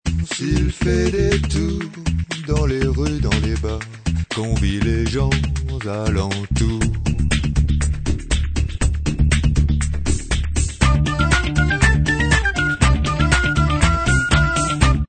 variétés influences diverses